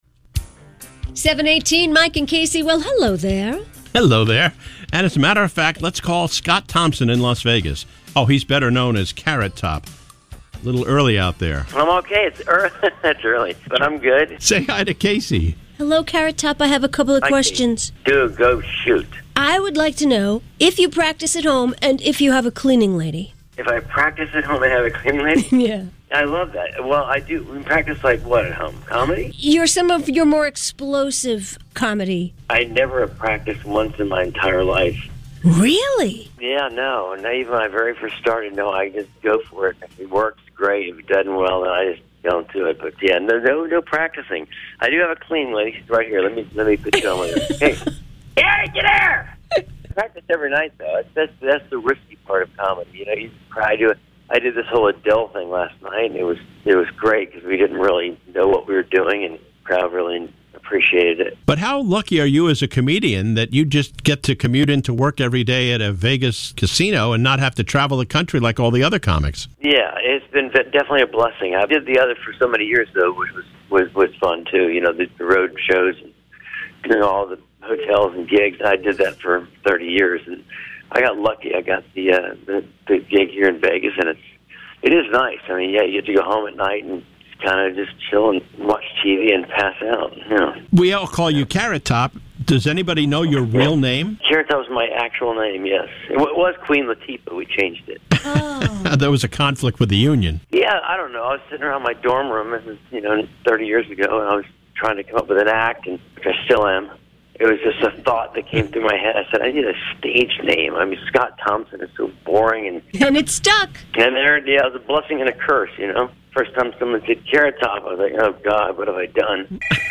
Comedian Carrot Top live from Las Vegas 2-10-22